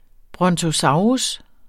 Udtale [ bʁʌntoˈsɑwʁus ]